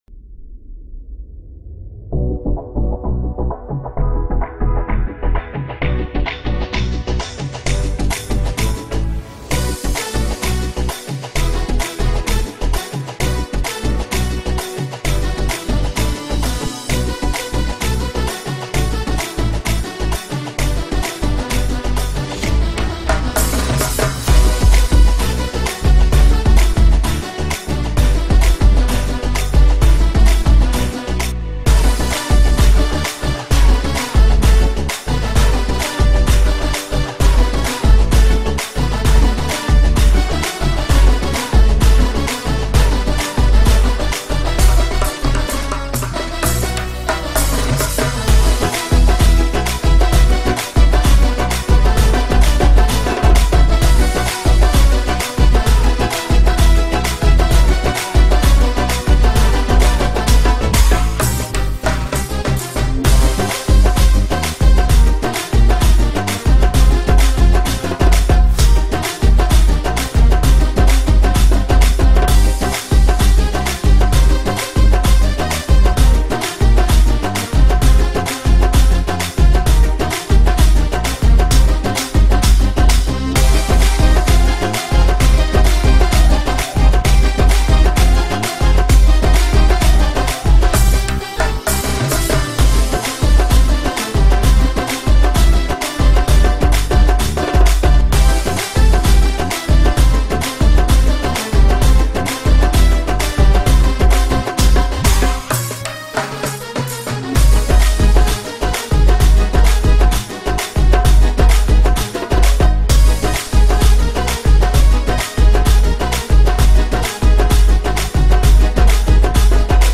New sinhala n tamil songs remix